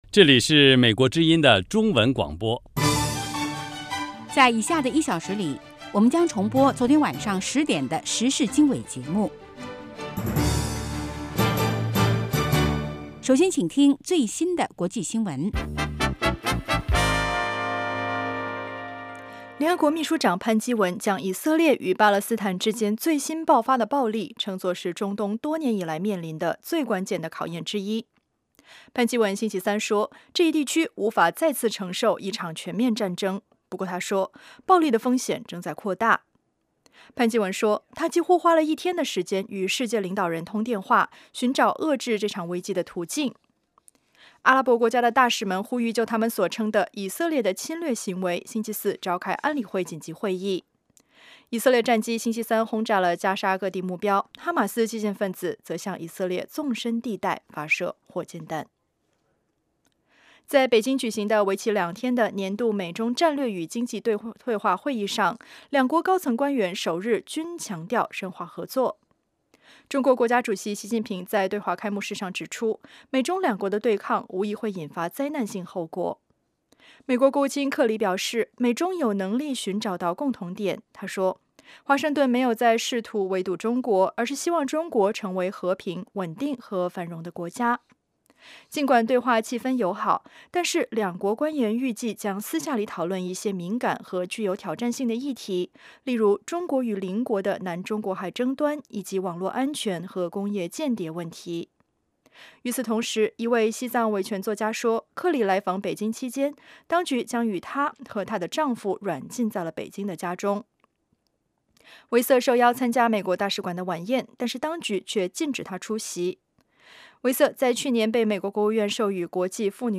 周一至周五：国际新闻 时事经纬(重播) 周六：时事经纬 听众热线 (重播) 北京时间: 上午8点 格林威治标准时间: 0000 节目长度 : 60 收听: mp3